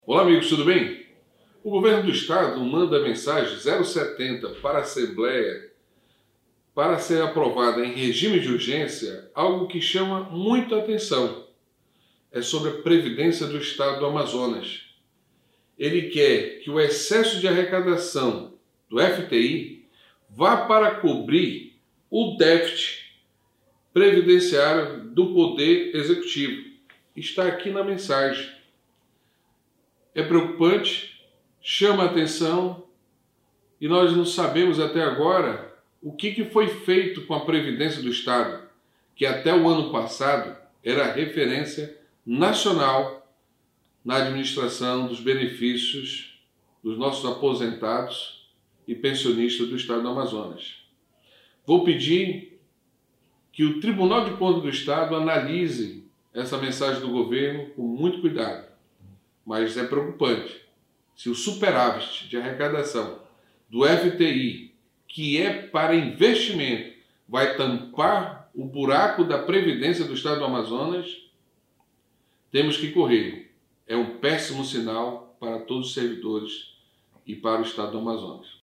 Nesta quinta-feira (1º/7), o deputado Dermilson Chagas (Podemos) usou a tribuna da Assembleia Legislativa do Amazonas (Aleam) para fazer duas denúncias contra o governador Wilson Lima, que enviou duas mensagens governamentais para a Casa, sendo uma para contrair empréstimo de R$ 1,5 bilhão junto ao Banco do Brasil, alegando de forma genérica que o recurso será utilizado para a recuperação da economia amazonense, sem dar mais explicações, e a segunda tem por finalidade utilizar o excesso de arrecadação bimestral das contribuições financeiras e os seus superávits financeiros anuais apurados, até o exercício de 2021, não utilizados, possam ser aplicados a cobertura do déficit previdenciário do Poder Executivo.